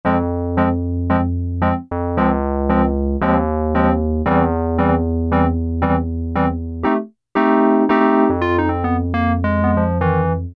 E08 - Reed Piano Classic Wurlitzer electric piano patch